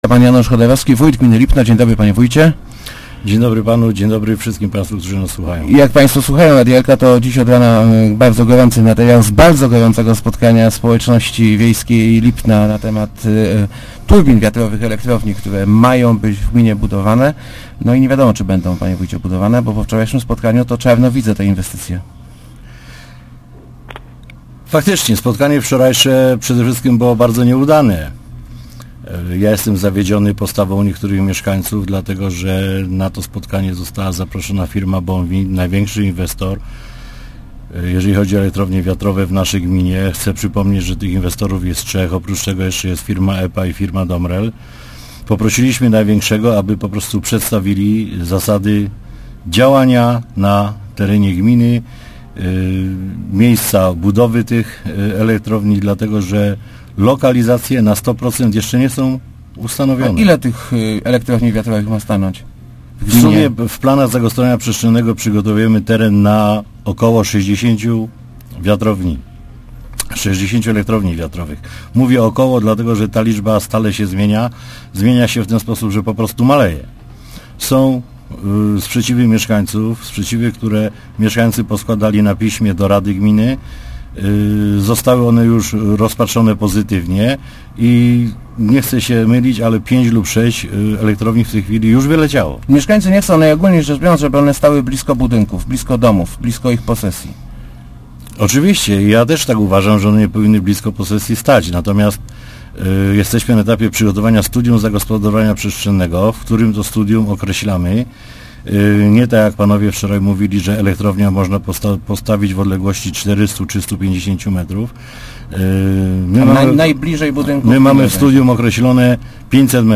Siłownie wiatrowe nie powstaną, jeżeli mieszkańcy sobie nie będą tego życzyli - zapewnił w Rozmowach Elki wójt Lipna Janusz Chodorowski. Przyznał on jednak, że jest wielu zwolenników turbin, choć nie było ich widać na poniedziałkowym spotkaniu mieszkańców wsi.